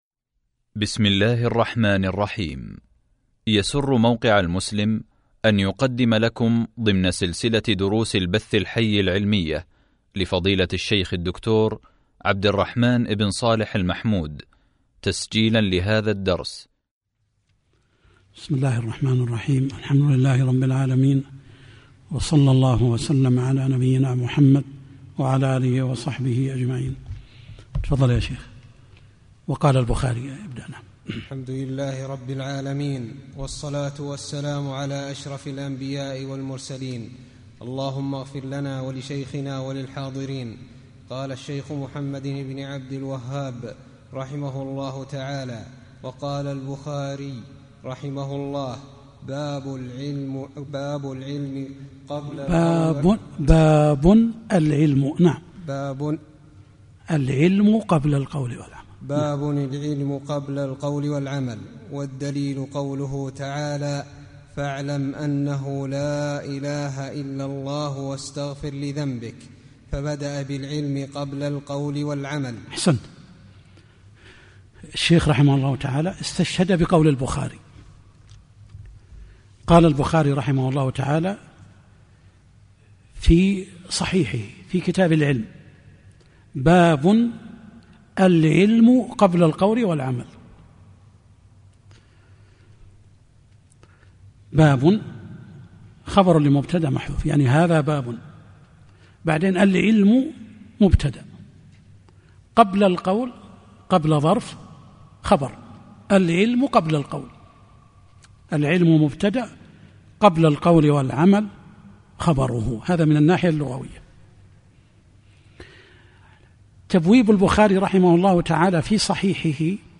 شرح الأصول الثلاثة | الدرس 3 | موقع المسلم